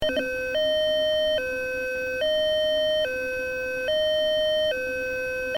Signalanlage
Eriston 150 Klang 1